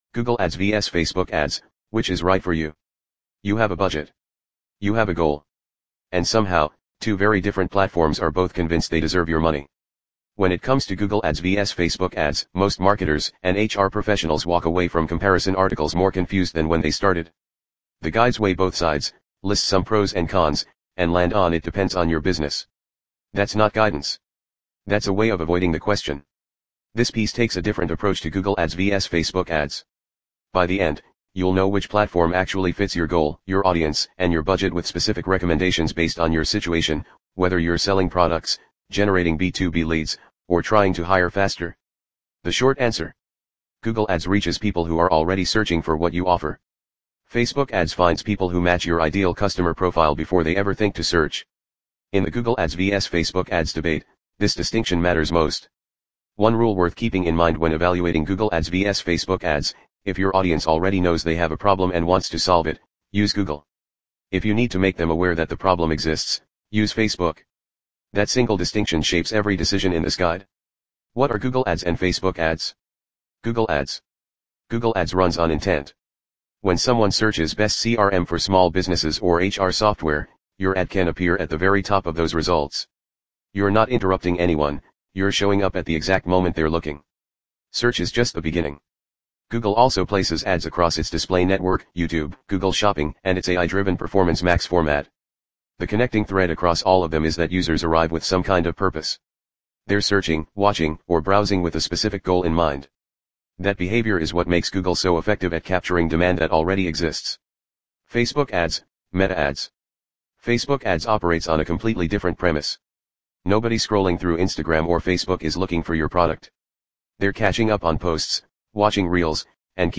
Read Aloud!